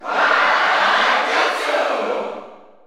Greninja_Cheer_German_SSBU.ogg.mp3